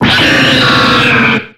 Cri de Cobaltium dans Pokémon X et Y.